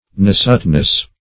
Search Result for " nasutness" : The Collaborative International Dictionary of English v.0.48: Nasutness \Na"sut*ness\, n. Quickness of scent; hence, nice discernment; acuteness.